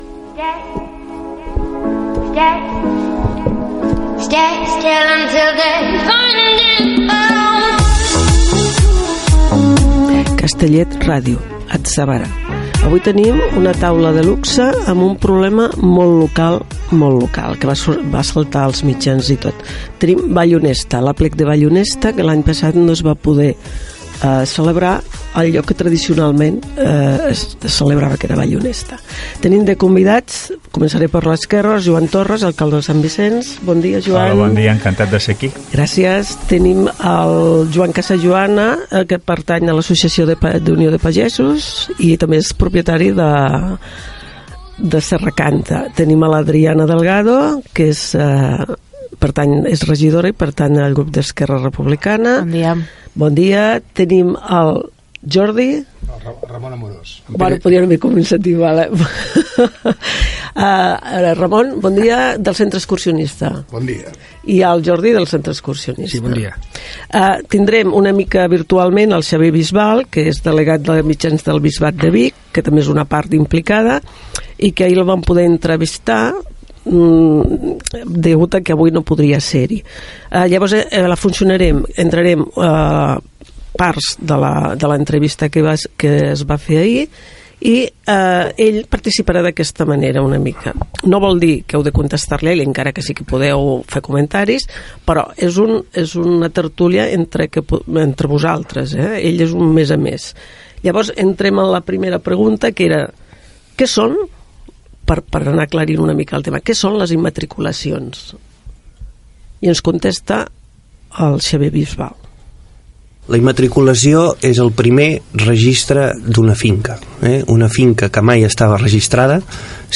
Identificació de l'emissora i del programa, presentació dels invitats que participaran a la tertúlia dedicada a l'aplec del Panellet de Vallhonesta que no es va poder celebrar, començant per una explicació de la immatriculació de propietats religioses.
Divulgació